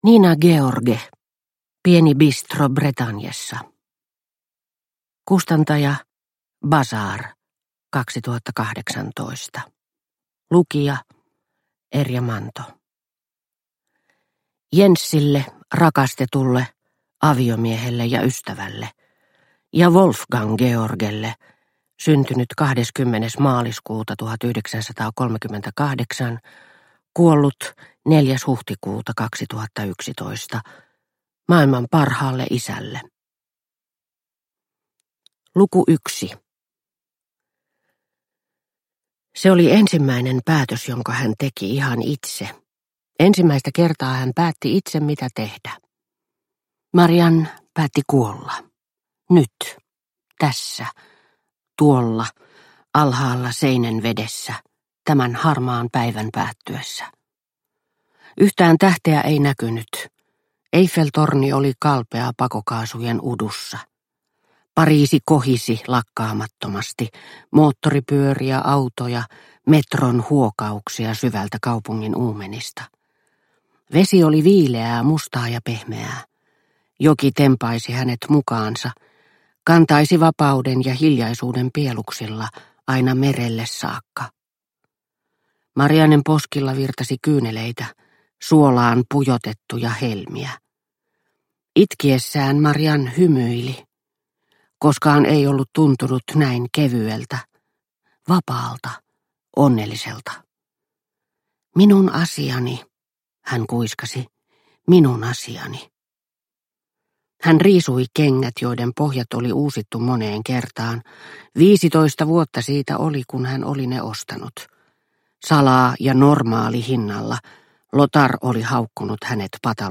Pieni bistro Bretagnessa – Ljudbok – Laddas ner